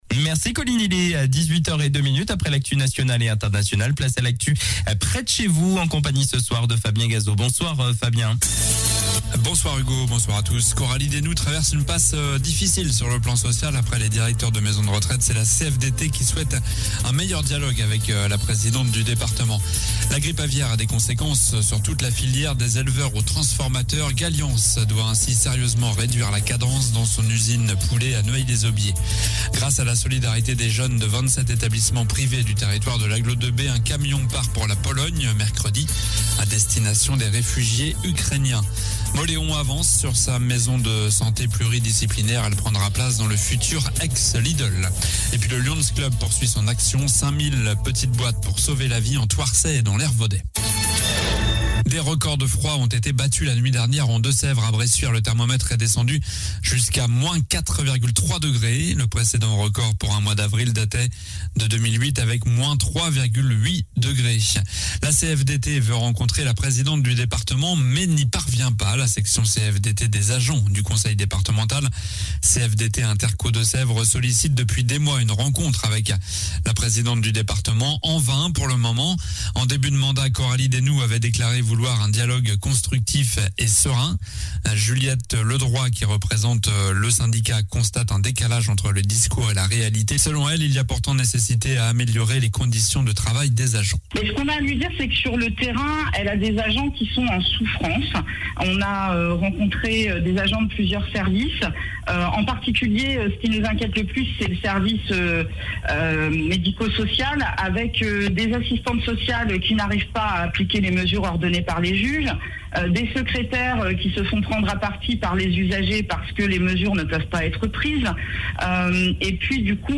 Journal du lundi 04 avril (soir)